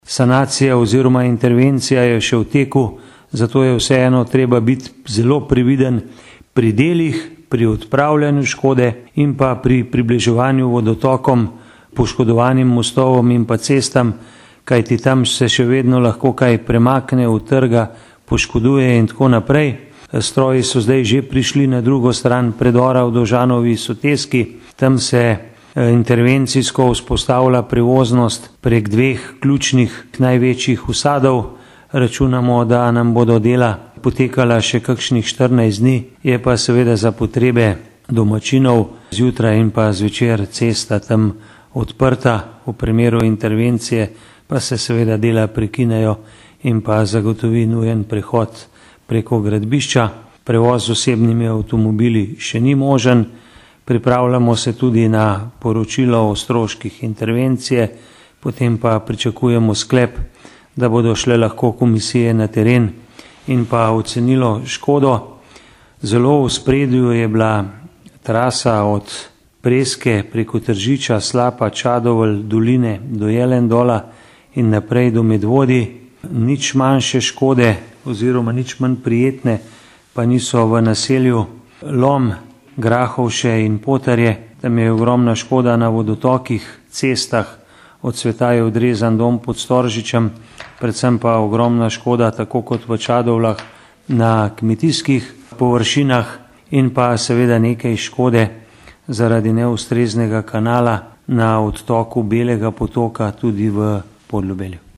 izjava_zupanobcinetrzicmag.borutsajovic.mp3 (2,5MB)